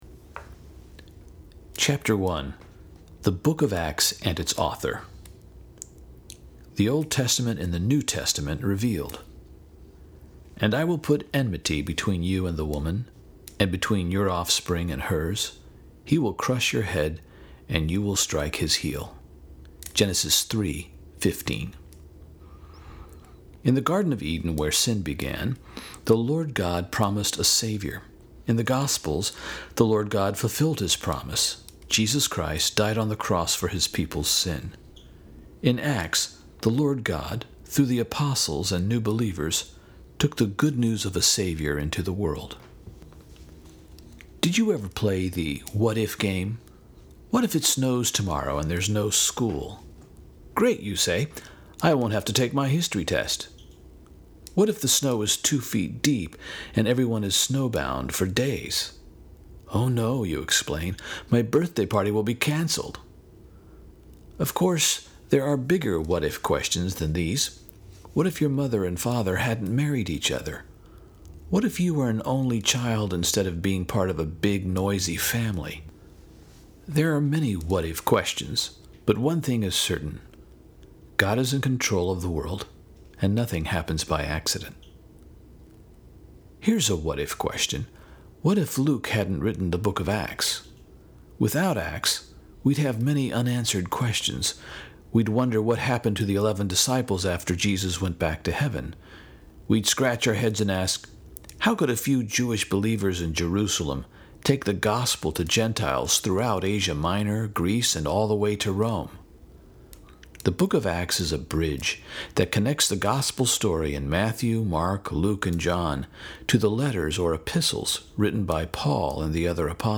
God’s Great Covenant: New Testament 2 – Audio Book